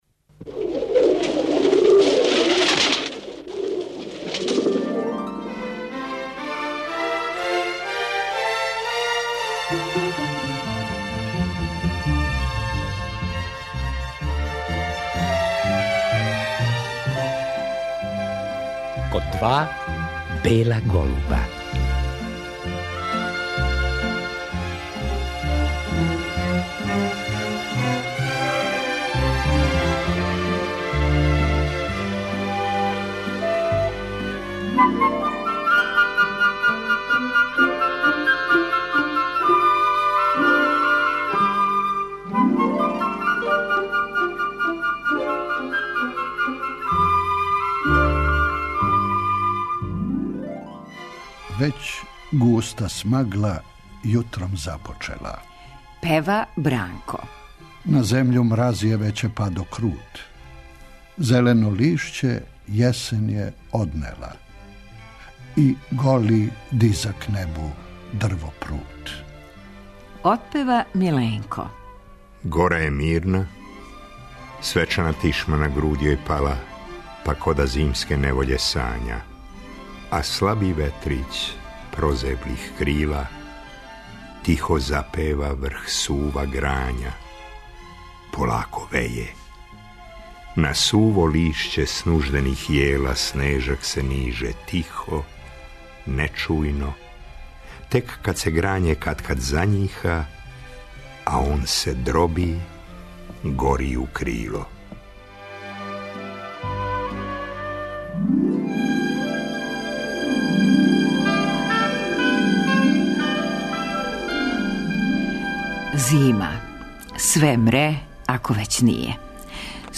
Уз стихове српских песника о зими слушамо музику Антонија Вивалдија.